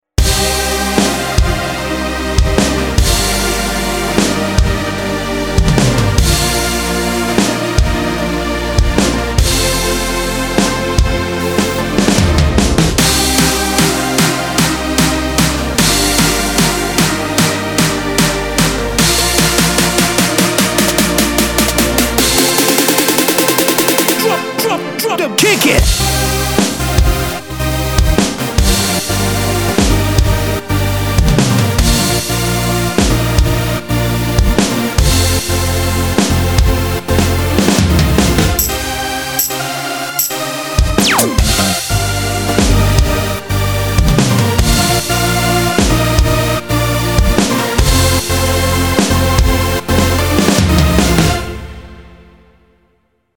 EDM 13 - dj 28 sv.MP3